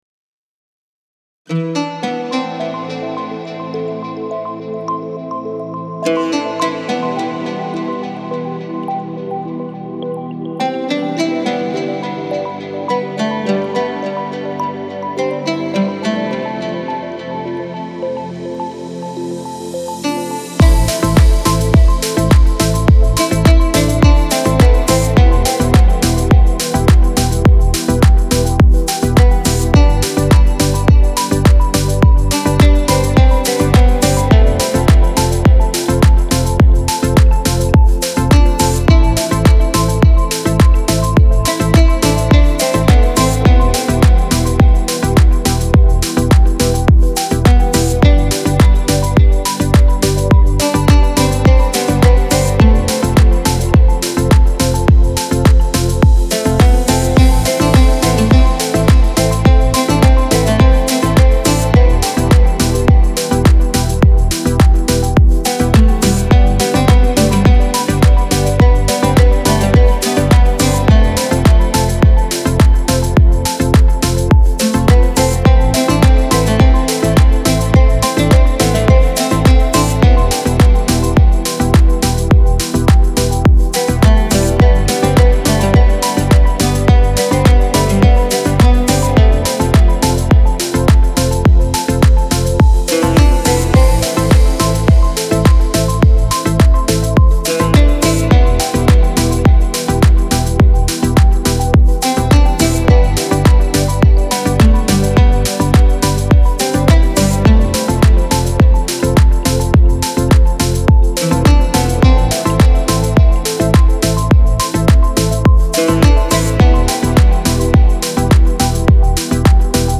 موسیقی بی کلام دیپ هاوس موسیقی بی کلام ریتمیک آرام